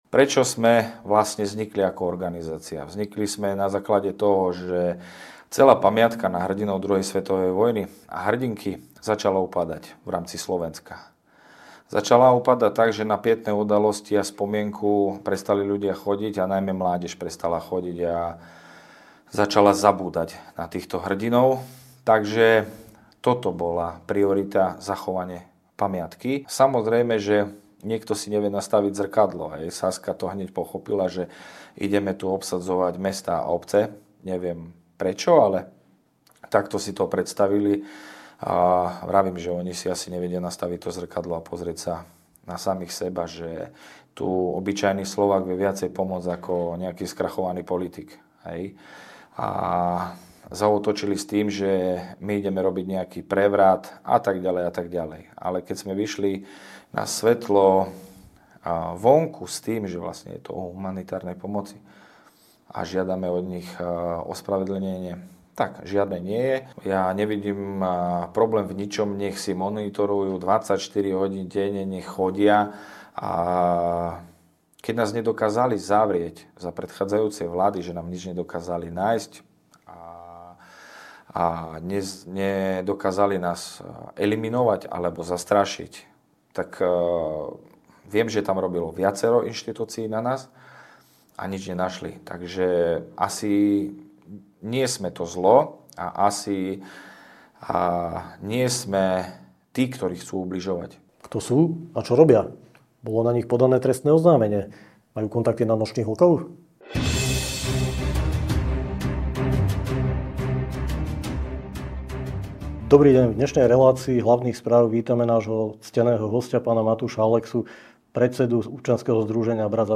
V štúdiu redakcie Hlavné správy sme privítali zaujímavého hosťa.